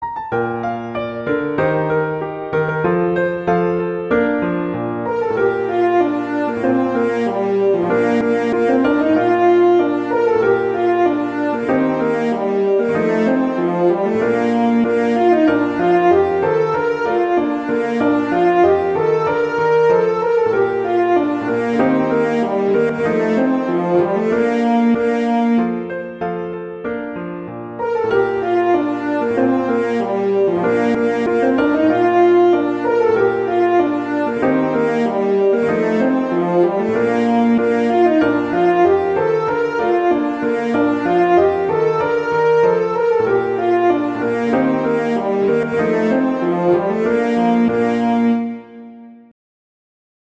arrangements for horn and piano
traditional, irish, children